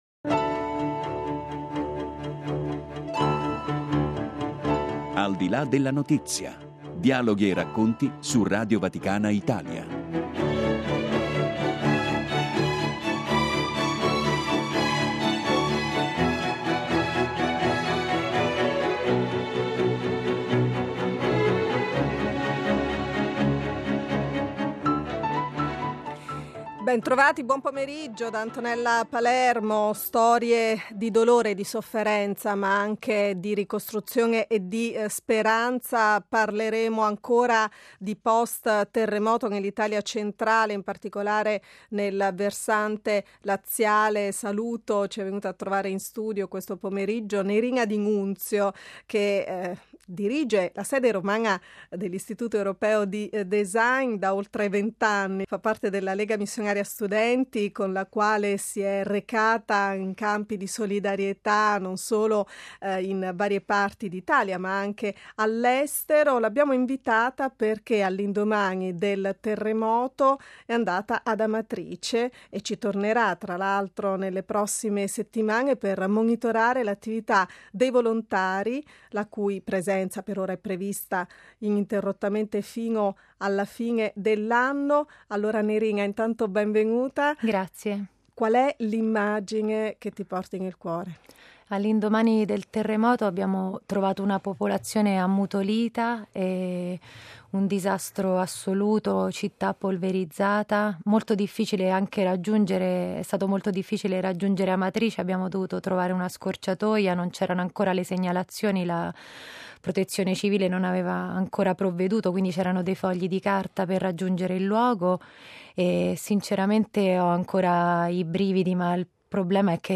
Un racconto dettagliato, denso, lucido e commosso di tre giorni passati accanto ai terremotati di Amatrice e di alcune frazioni limitrofe.